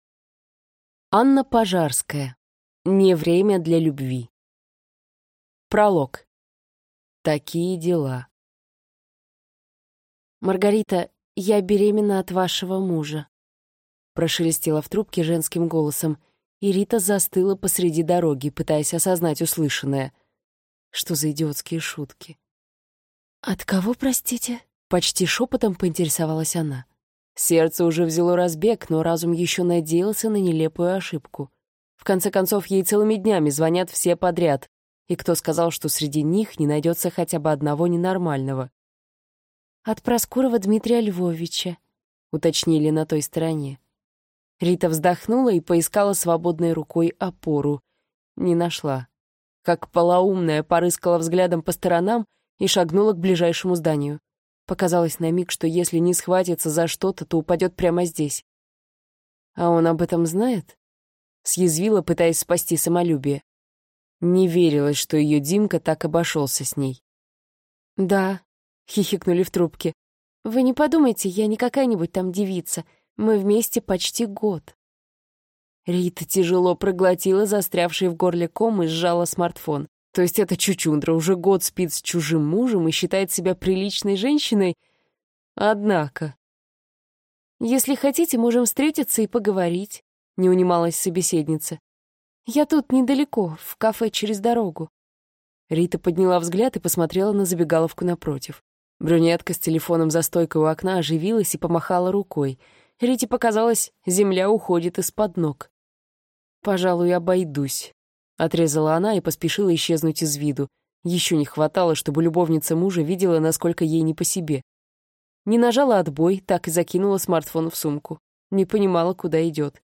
Аудиокнига (Не) время для любви | Библиотека аудиокниг